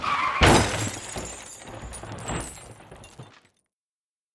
car-crash-sound-eefect.mp3